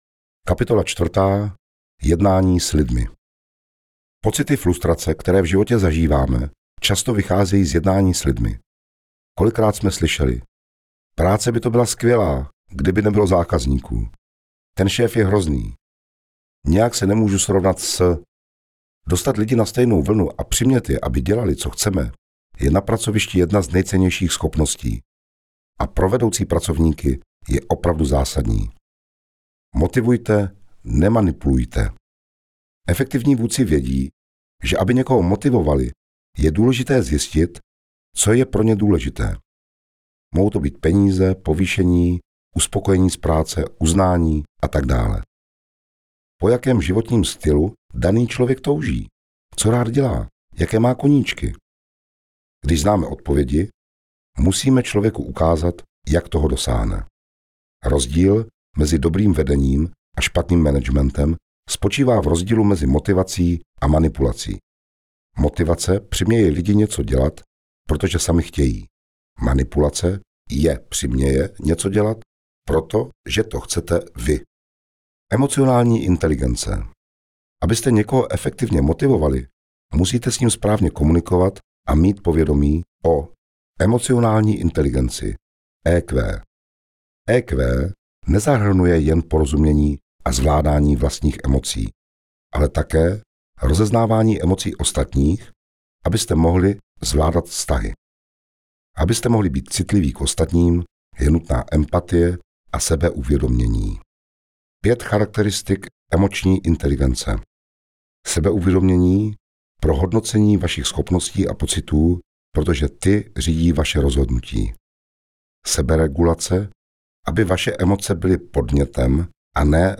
Audiokniha Komunikací k Vítězství